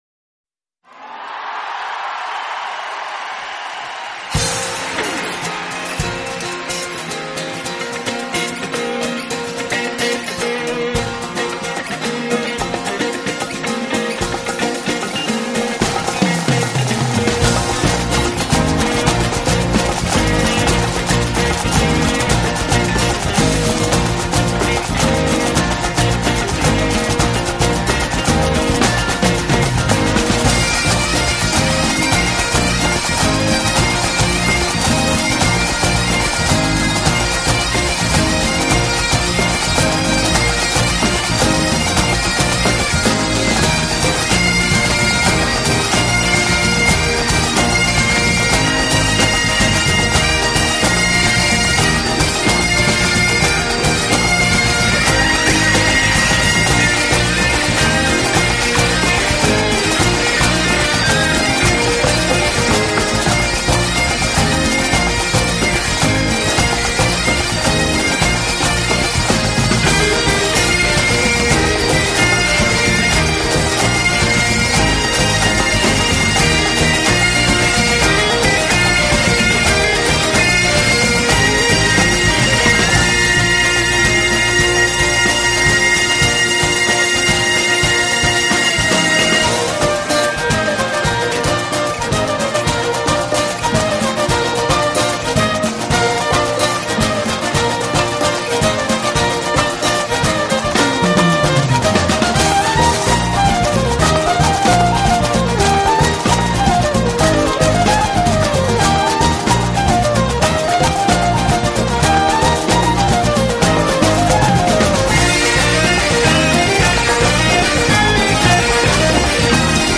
genre: New Age